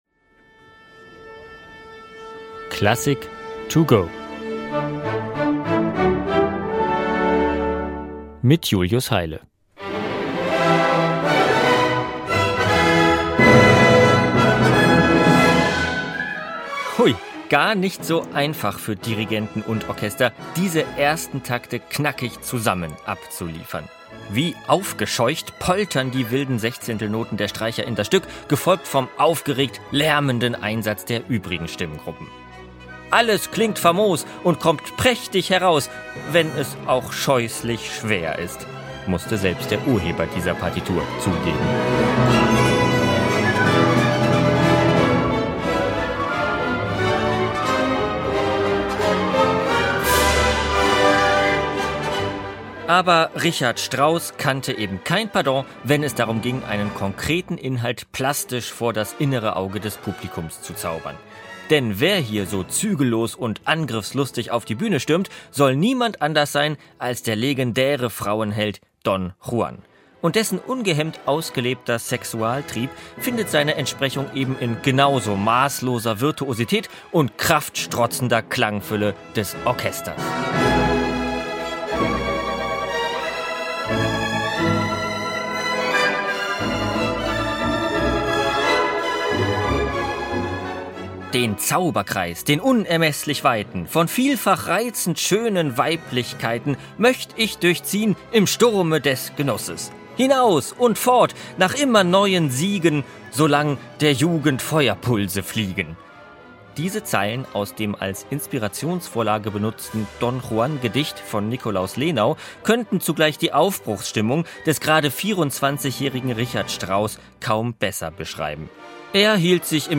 kurzen Werkeinführung für unterwegs